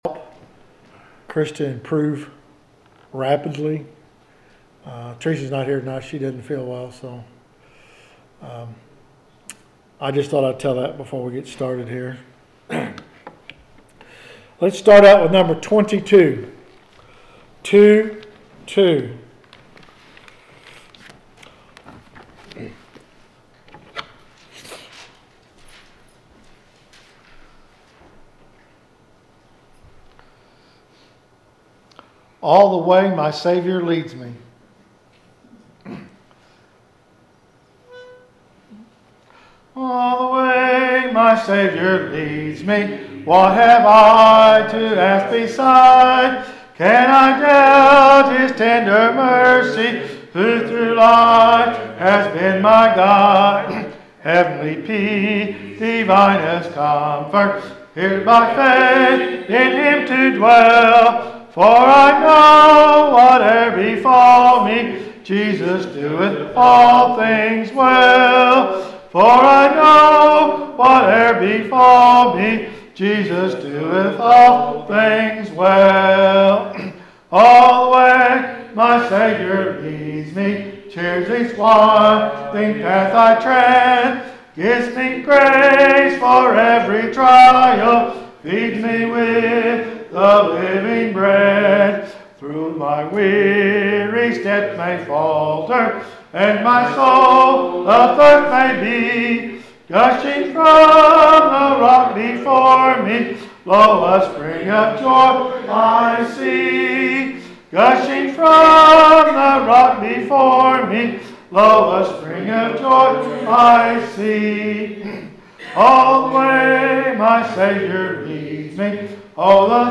The sermon is from our live stream on 11/16/2025